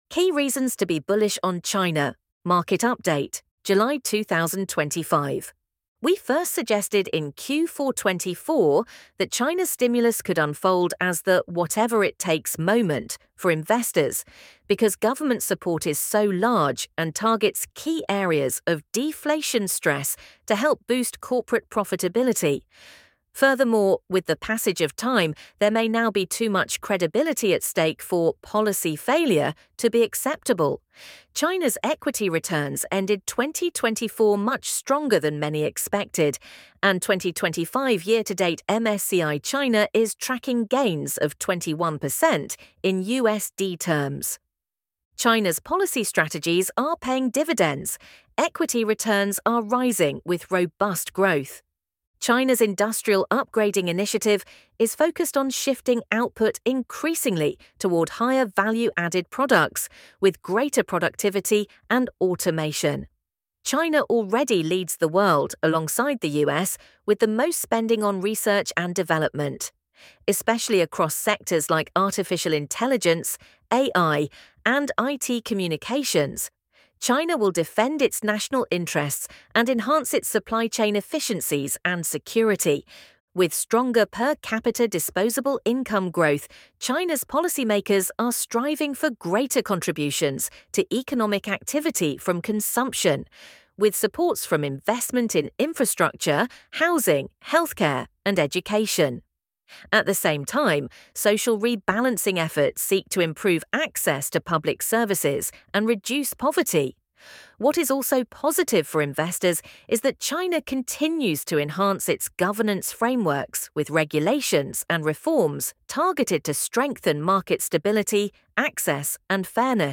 ElevenLabs_Key_reasons_to_be_bullish_on_China.mp3